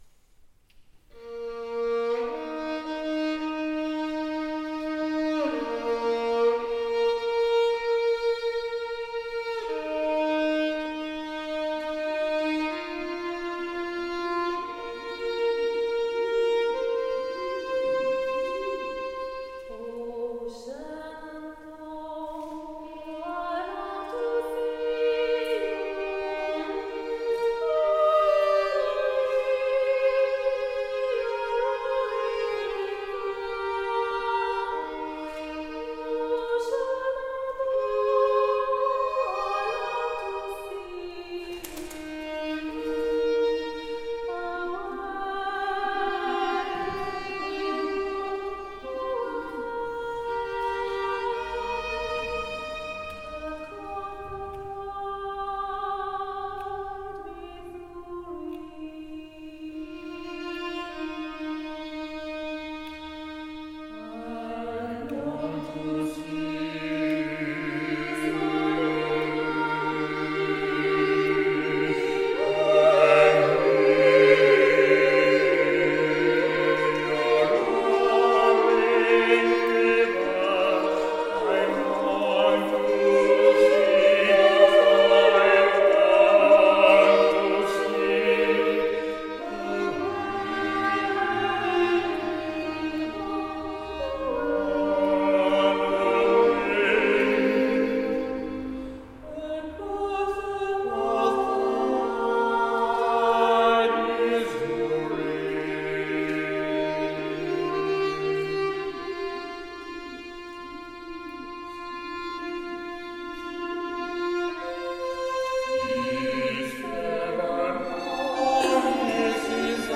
Musique chorale américaine
Extraits de la restitution de fin de stage dans l'église de Sainte Thumette à Penmarc'h, le 3 mai 2024